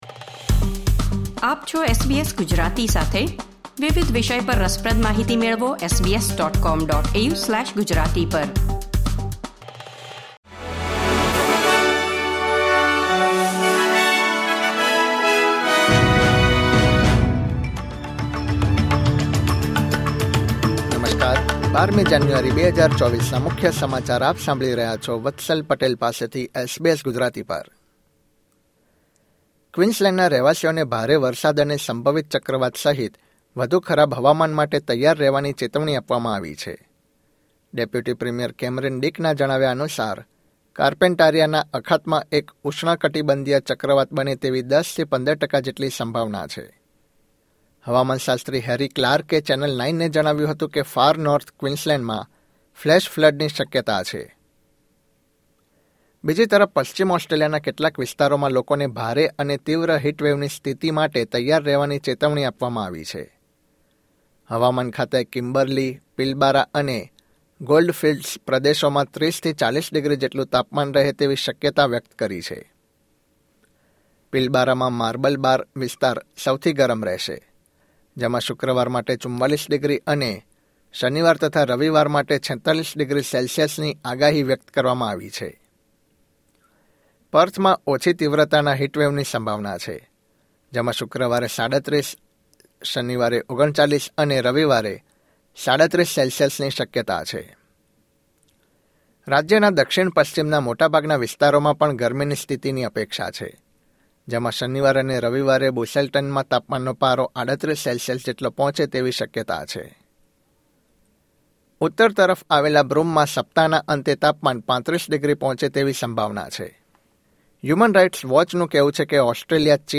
SBS Gujarati News Bulletin 12 January 2024